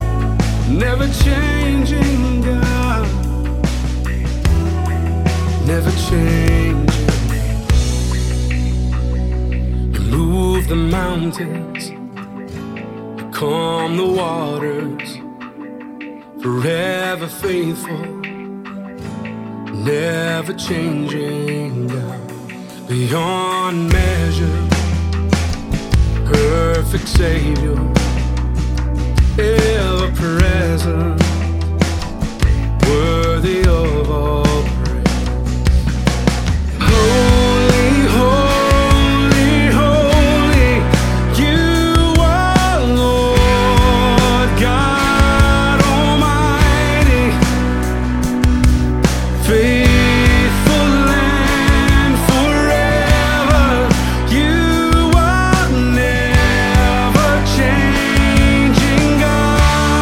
zeitgemäße, gemeindetaugliche Lobpreismusik
• Sachgebiet: Praise & Worship